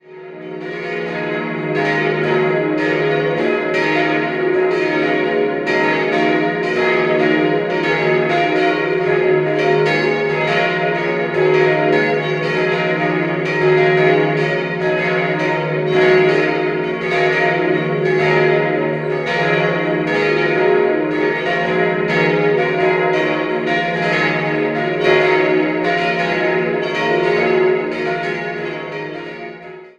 Die barocke Ausstattung mit drei Altären wurde aus dem alten, 1956 abgebrochenen Gotteshaus überführt. 5-stimmiges Geläut: es'-f'-as'-b'-des'' Die kleine Glocke wurde 1755 von Josef Kern in Augsburg gegossen, alle anderen stammen von Karl Czudnochowsky aus dem Jahr 1953.